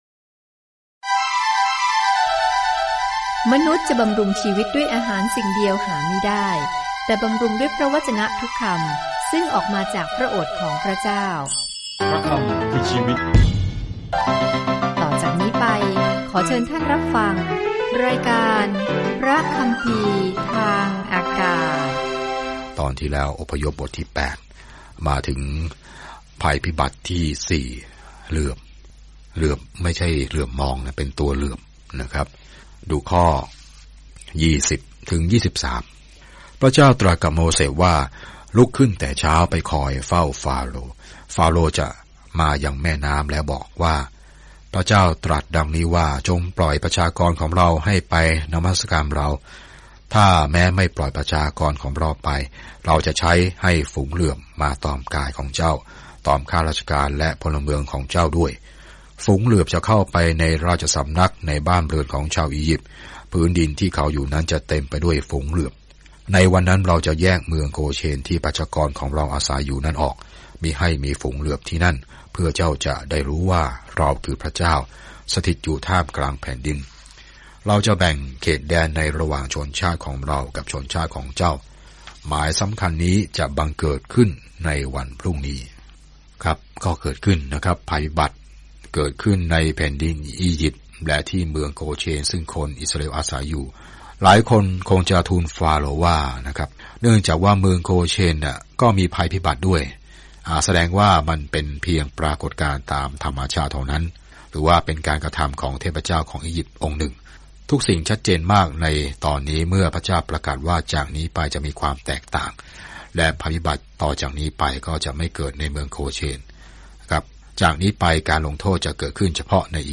อพยพติดตามการหลบหนีของอิสราเอลจากการเป็นทาสในอียิปต์และบรรยายถึงทุกสิ่งที่เกิดขึ้นระหว่างทาง เดินทางทุกวันผ่าน Exodus ในขณะที่คุณฟังการศึกษาด้วยเสียงและอ่านข้อที่เลือกจากพระวจนะของพระเจ้า